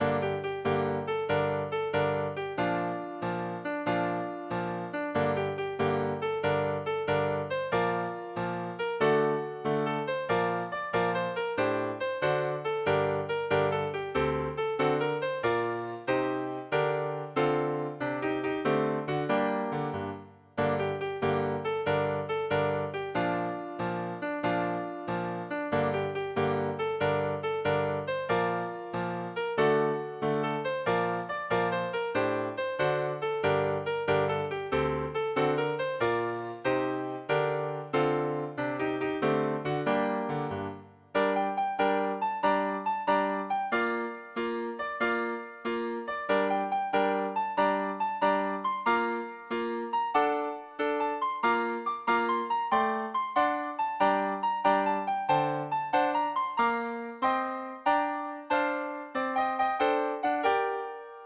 Midi File, Lyrics and Information to When Johnny Comes Marching Home